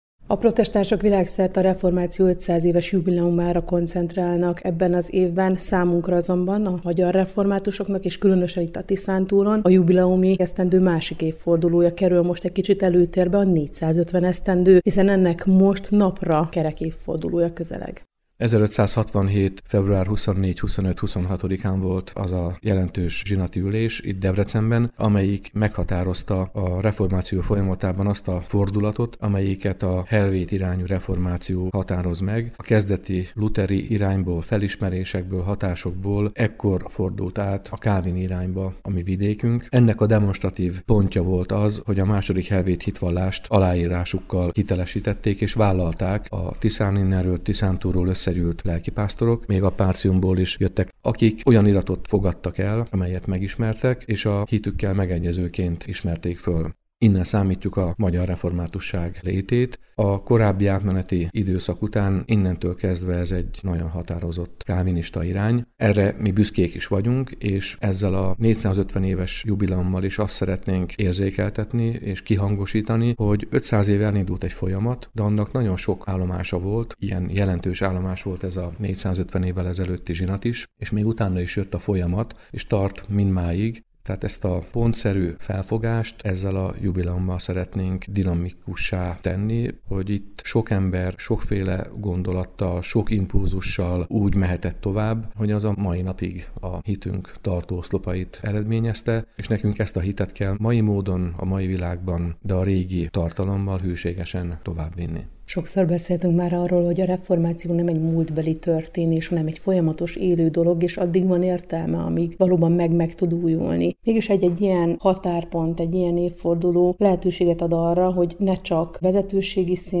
A Tiszántúli Református Egyházkerület ünnepi közgyűlése és hálaadó istentiszteleti rend - hanganyaggal